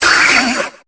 Cri de Grindur dans Pokémon Épée et Bouclier.